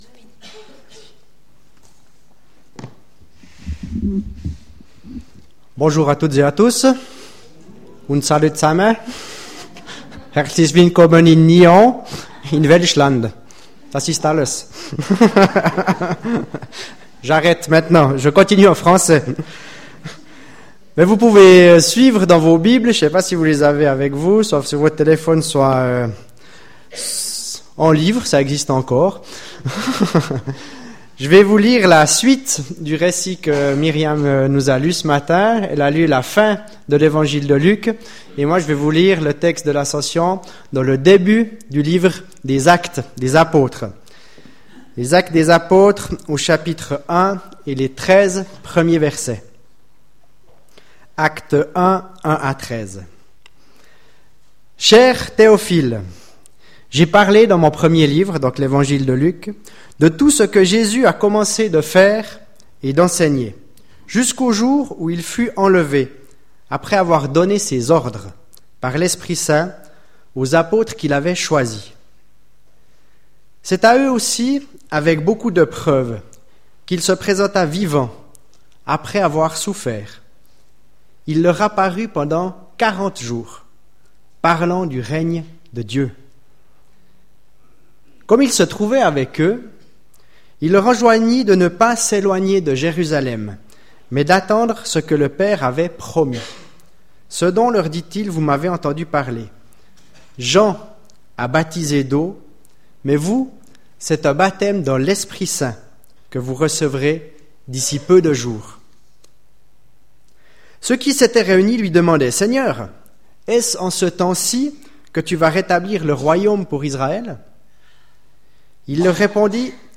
Culte du 28 mai 2017